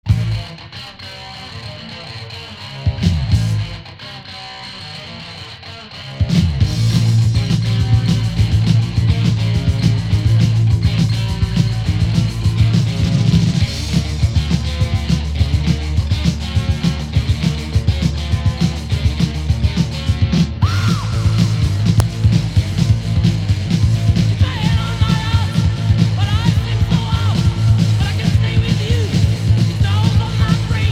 Hard rock
Hard heavy Unique 45t retour à l'accueil